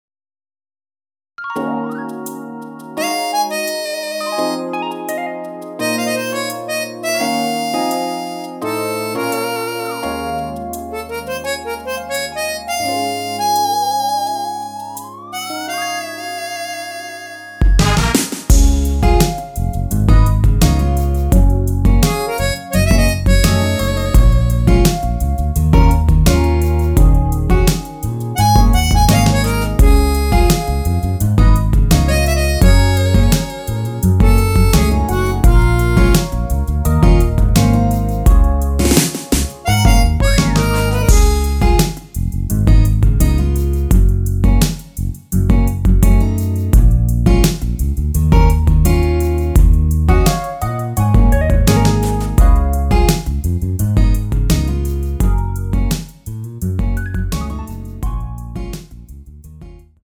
원키에서(-1)내린 MR입니다.
Db
앞부분30초, 뒷부분30초씩 편집해서 올려 드리고 있습니다.